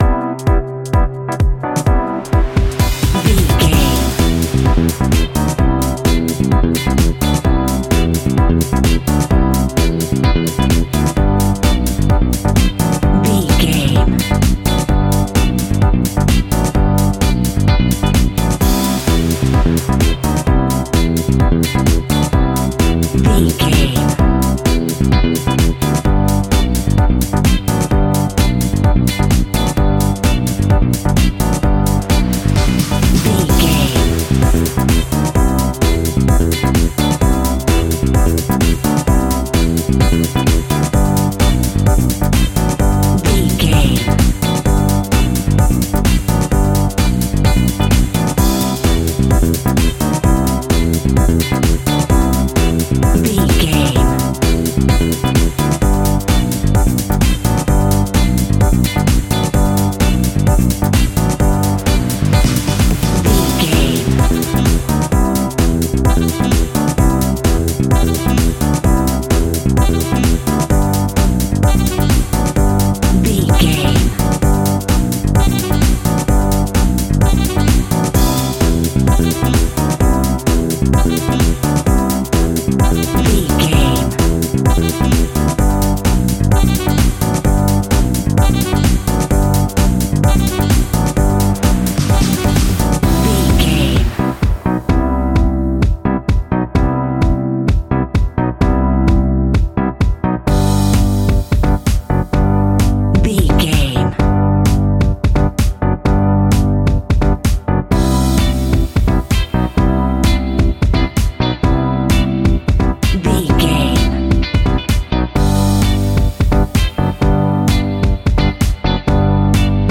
Ionian/Major
groovy
uplifting
bouncy
bass guitar
drums
synthesiser
saxophone
nu disco
upbeat